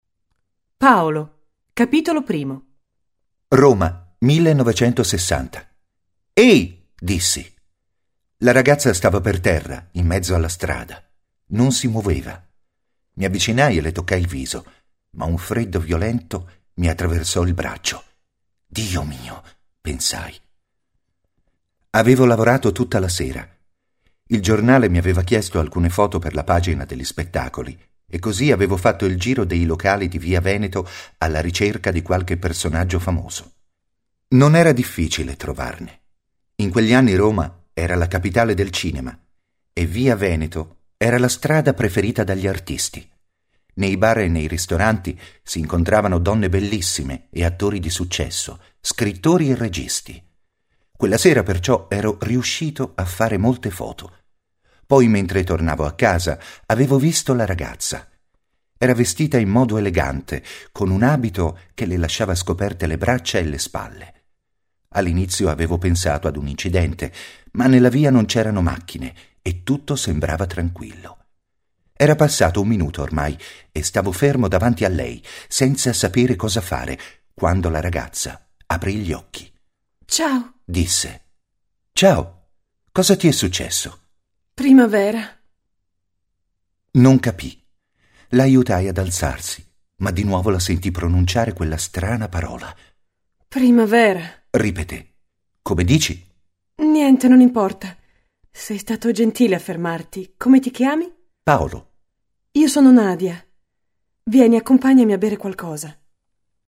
Il volume fa parte della Nuova Serie della collana Italiano Facile, letture graduate per studenti stranieri con esercizi e versione audio del testo, con voci di attori professionisti ed effetti sonori realistici e coinvolgenti.